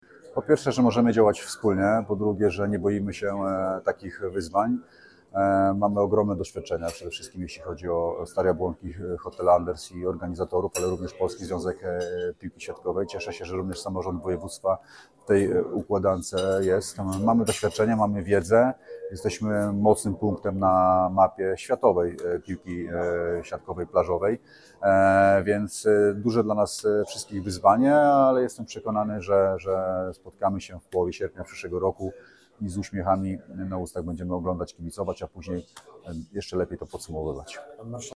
– powiedział Marcin Kuchciński, marszałek województwa warmińsko-mazurskiego.
wypowiedz_Marszalek_Kuchcinski.mp3